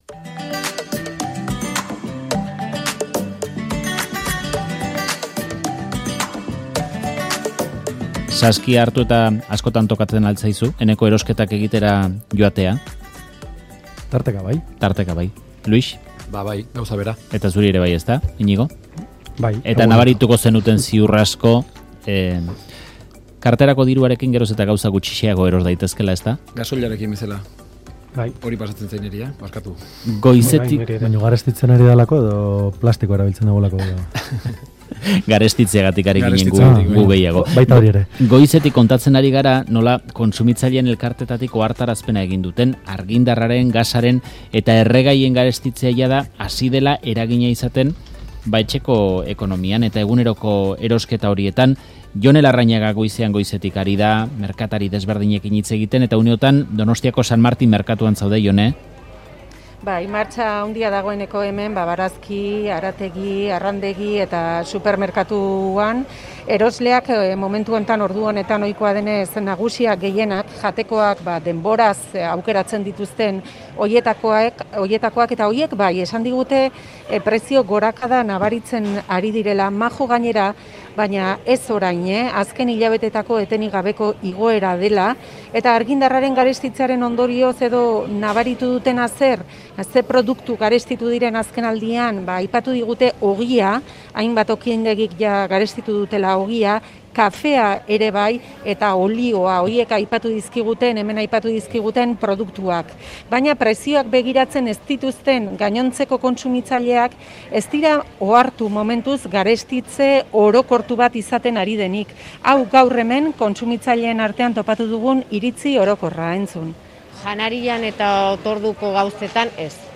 Audioa: Erosketa saskiaren garestitzeaz, Faktoria albistegia, Euskadi Irratia